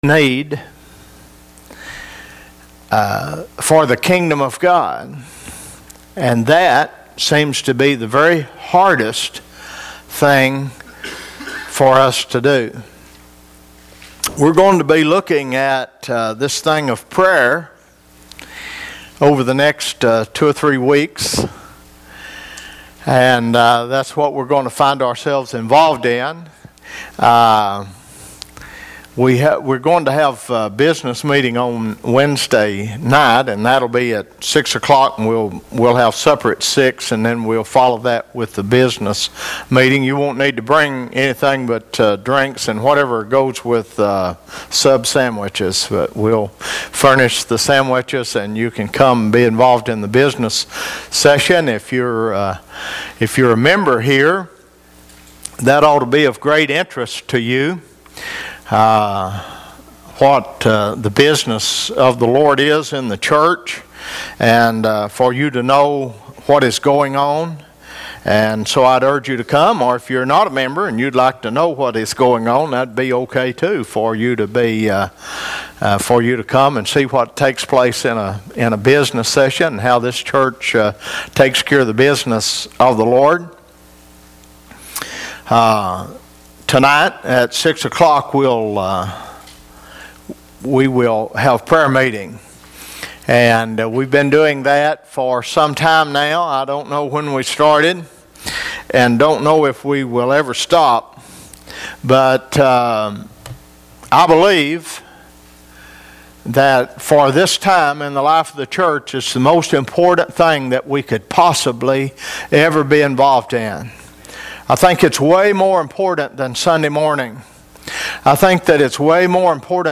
Required fields are marked * Comment * Name * Email * Website ← Newer Sermon Older Sermon →